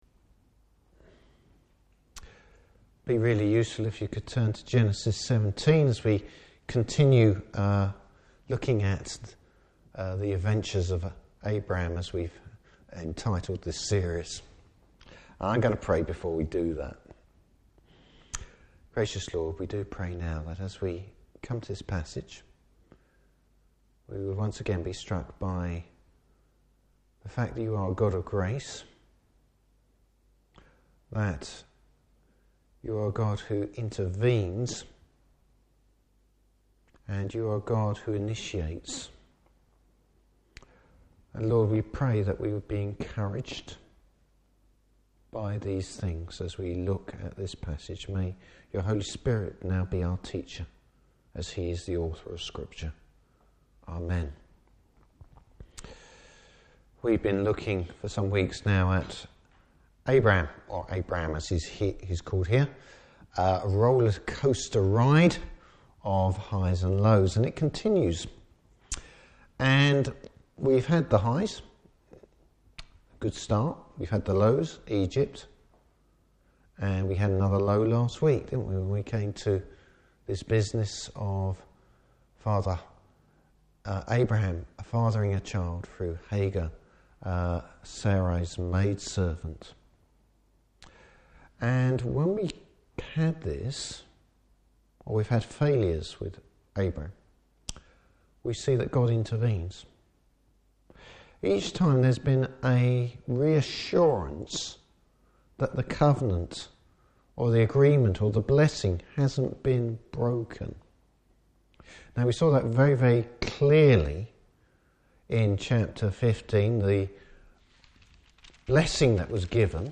Service Type: Evening Service How the Lord initiated every aspect of his covenant with Abraham.